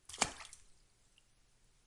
水花 6
描述：水飞溅的声音在被采取的桶的户内在小公寓。使用H2N变焦记录仪录制。
Tag: 室内 飞溅